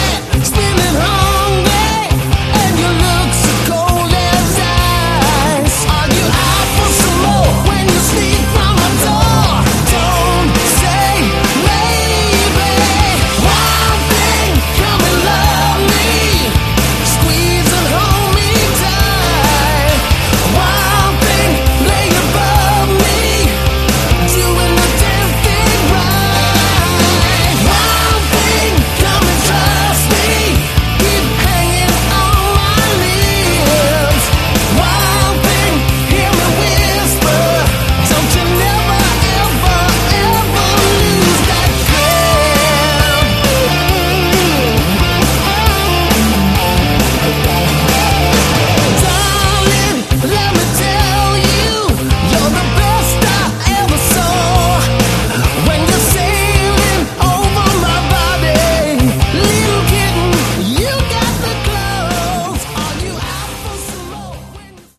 Category: AOR
vocals